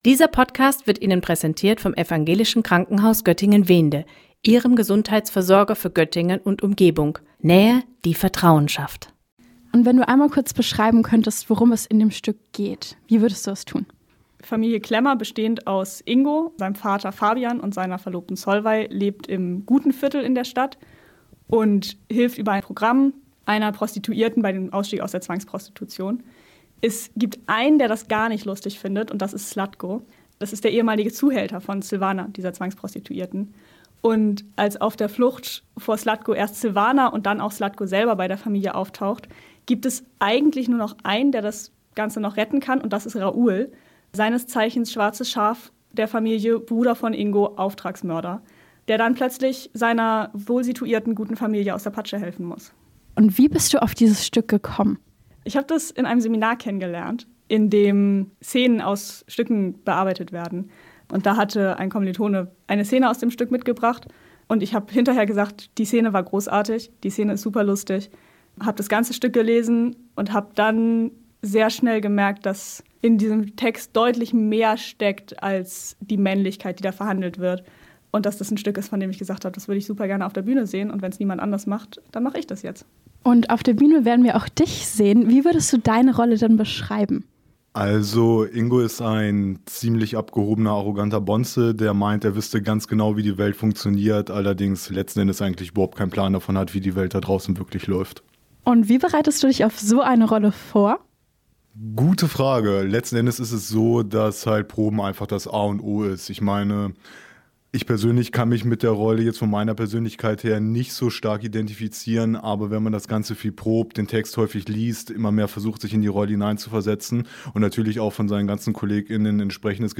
Beiträge > Testosteron im Test – Interview zum neuen Stück im Theater im OP - StadtRadio Göttingen